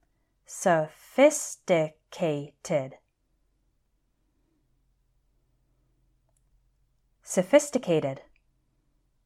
I’ll say each word twice – once a little more slowly with some separation between the syllables, and then once at normal speed, and I’d like you to repeat after me both times.
pre-LIM-i-na-ry: preliminary
dif-fer-EN-ti-ate: differentiate
phar-ma-CEU-ti-cal: pharmaceutical
en-tre-pre-NEUR-ship: entrepreneurship
re-pre-SEN-ta-tive: representative